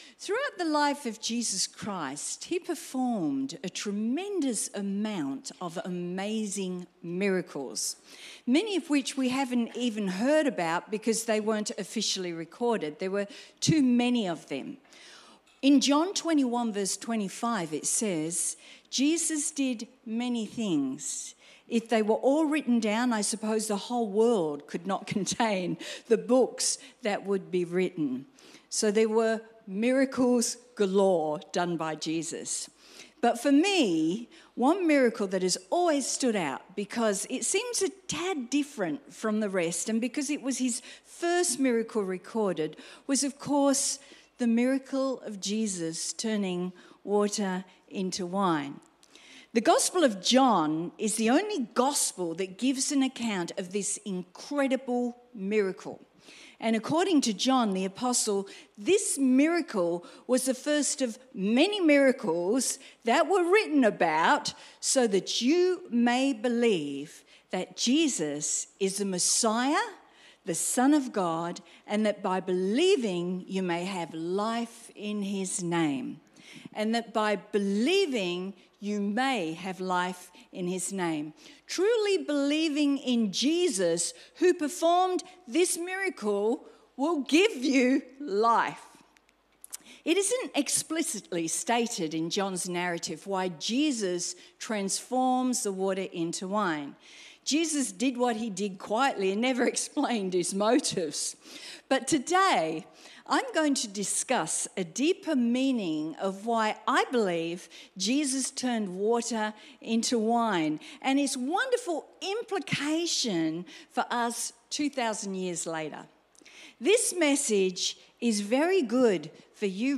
Sermon Transcript Throughout the life of Jesus Christ, He performed a tremendous amount of amazing miracles.